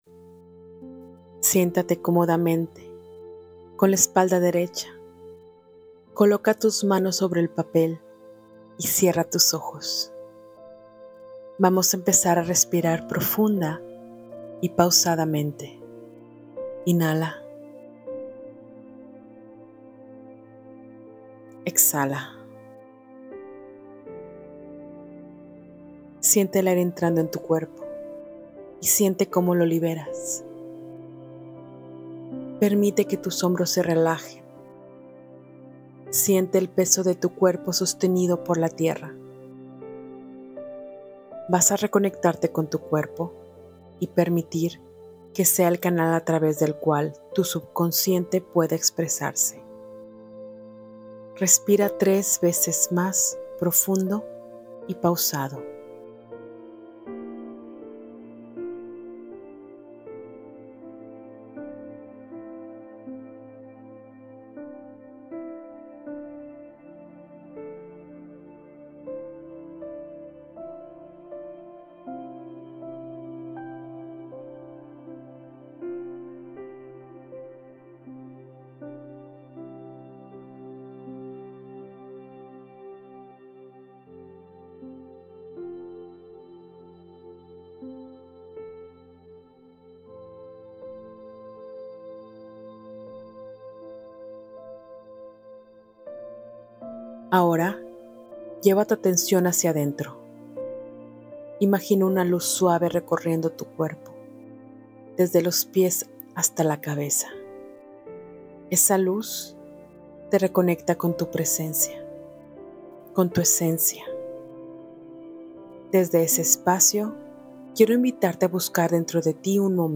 Descargar la Meditacion en Audio